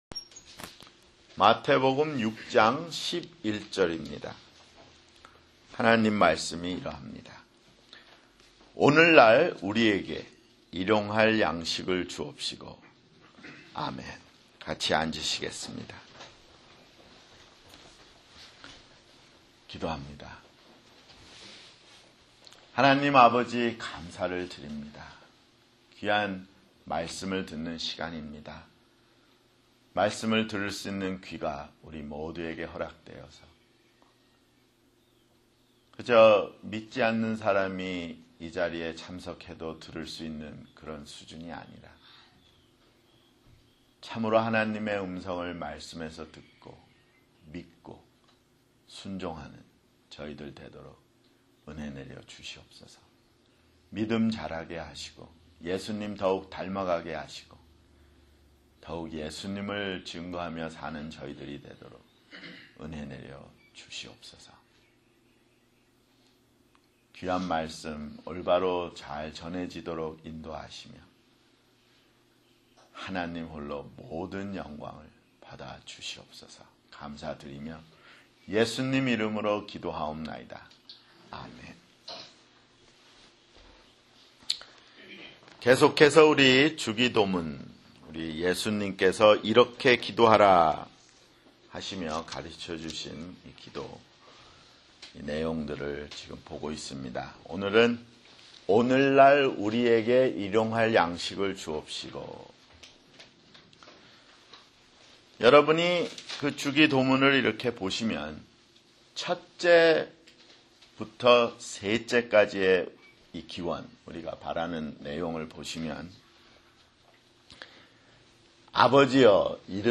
[주일설교] 주기도문 (5)